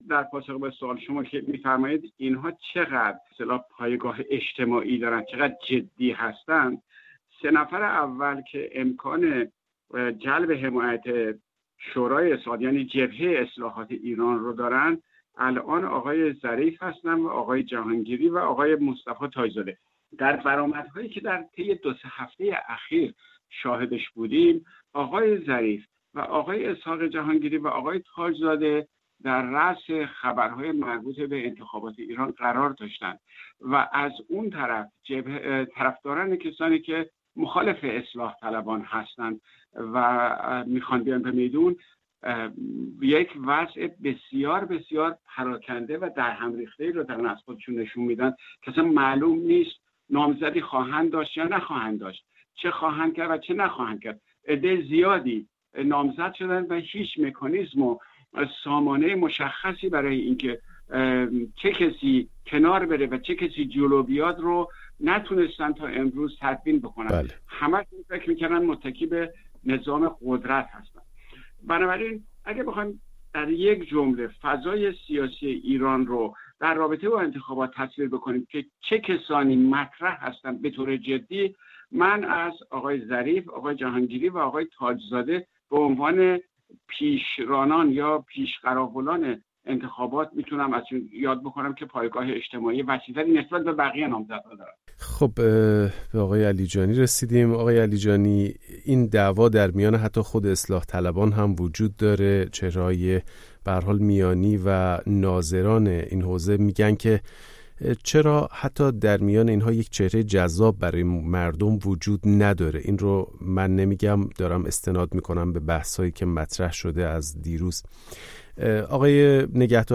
میزگردی رادیویی